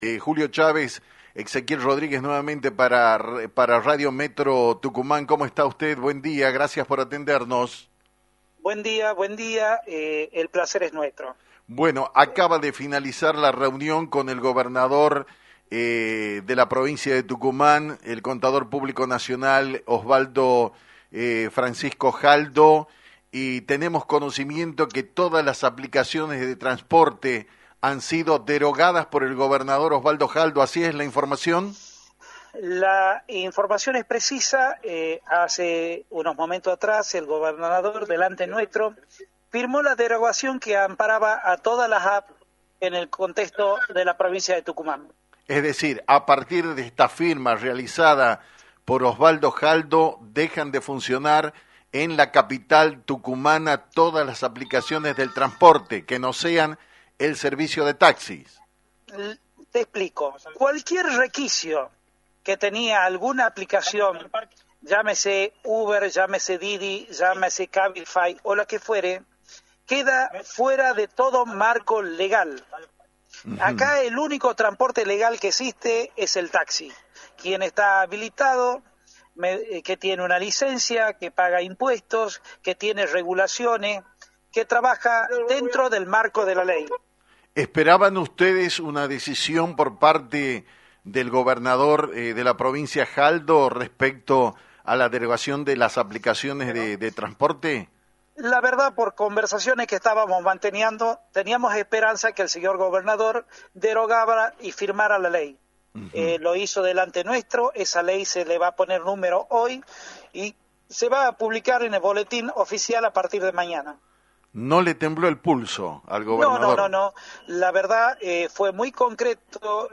En dialogo exclusivo con Actualidad en Metro